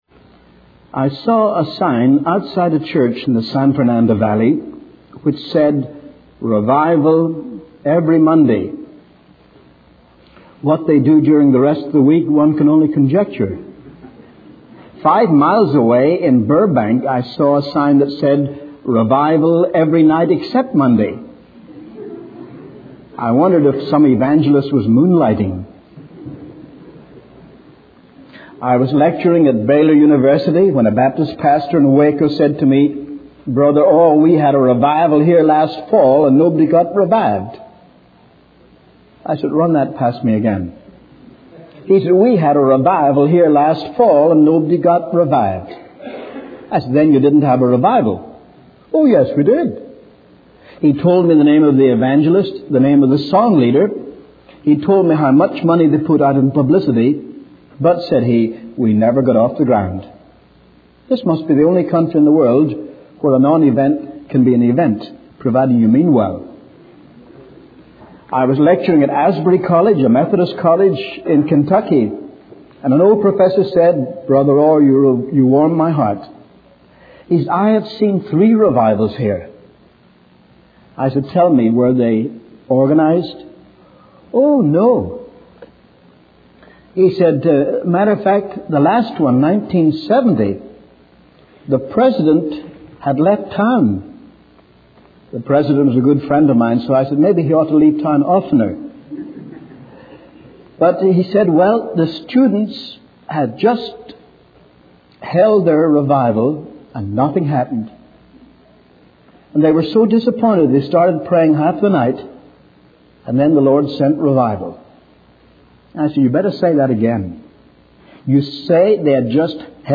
In this sermon, the speaker discusses the concept of evangelism and its importance in spreading the word of God. He defines evangelism as presenting Jesus Christ in the power of the Spirit, leading people to trust in Him as Savior and serve Him as Lord.